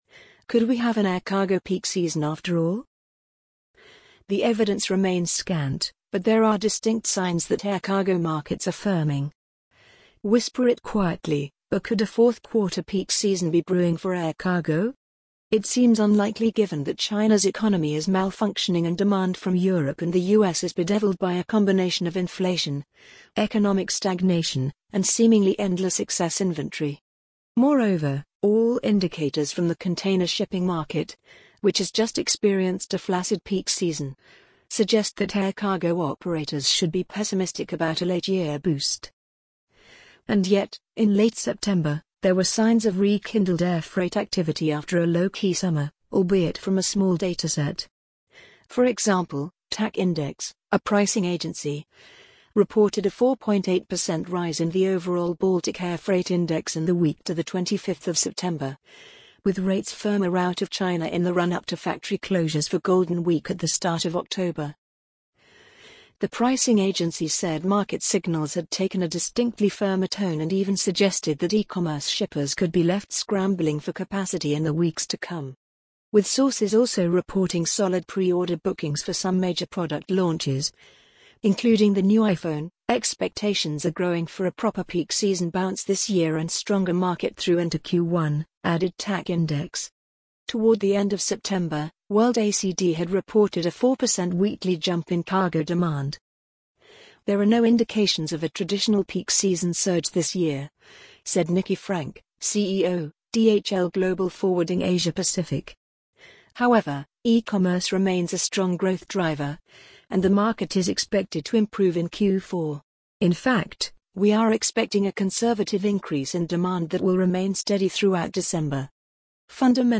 amazon_polly_46652.mp3